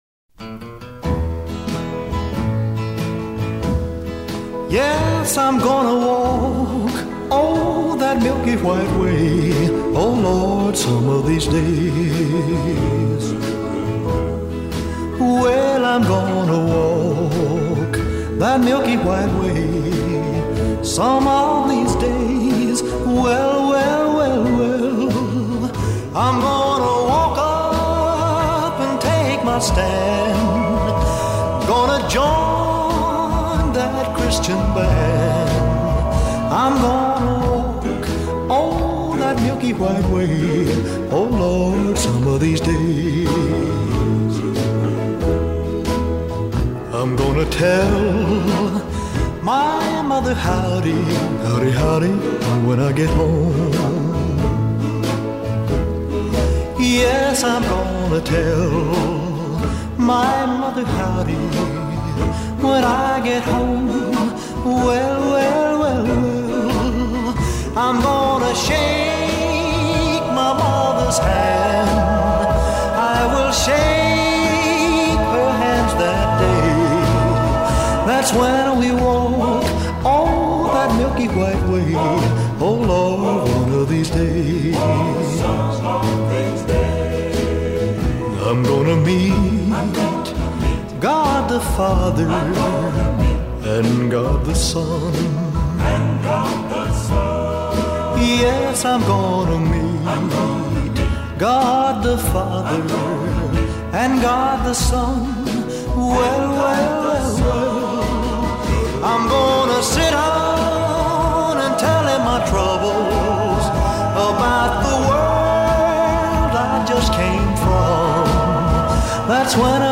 音乐类型：西洋音乐